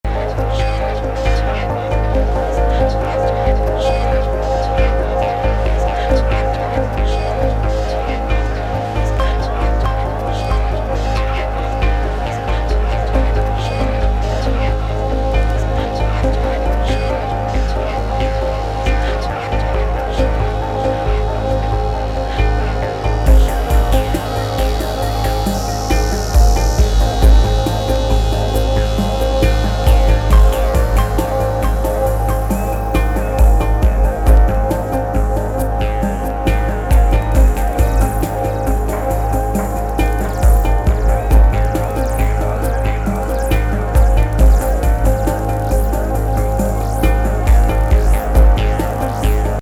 電子音によるアンビエントな雰囲気から心地良いビートが絡められるA面